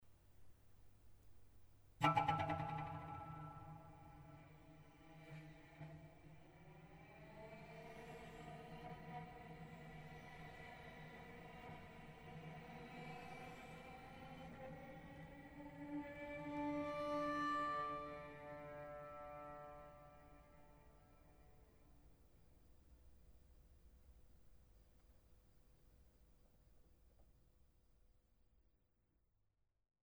Violoncello und Arrangements